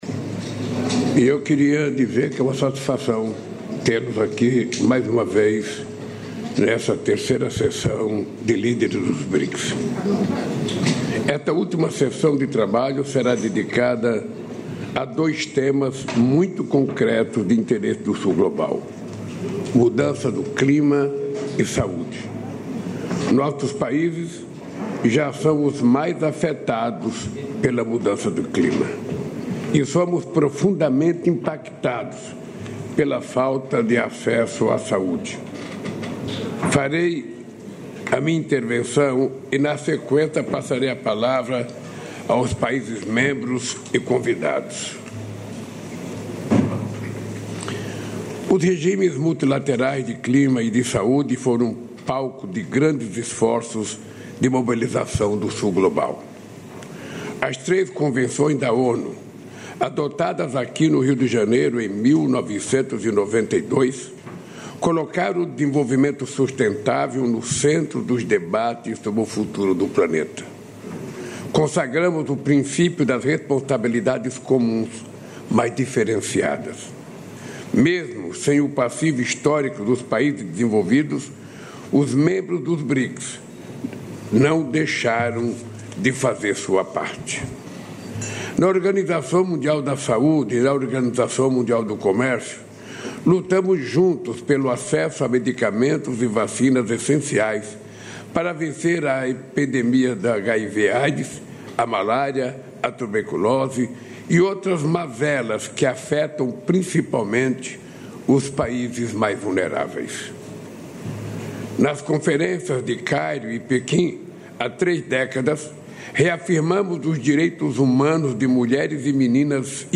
Íntegra do discurso do presidente da República, Luiz Inácio Lula da Silva, nesta terça-feira (11), na cerimônia de inauguração do Centro de Desenvolvimento de Tecnologia Híbrida Flex e de anúncio de contratações da Stellantis, em Betim (MG).